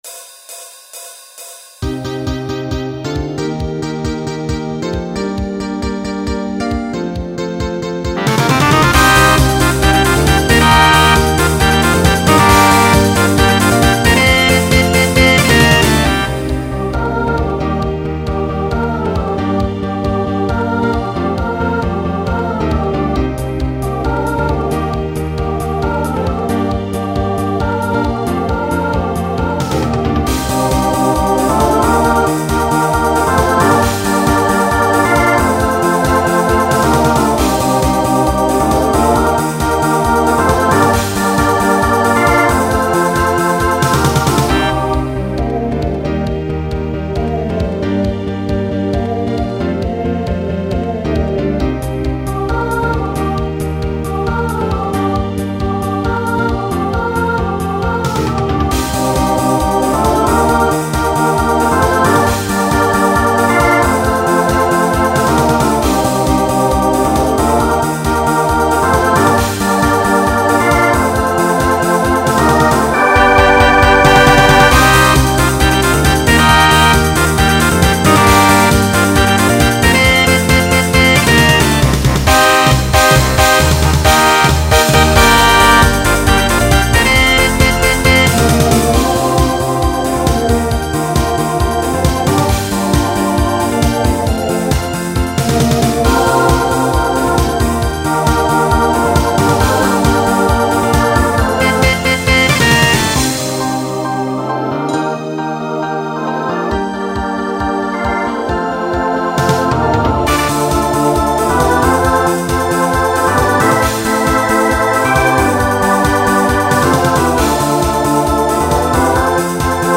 Genre Pop/Dance , Rock Instrumental combo
Opener Voicing SATB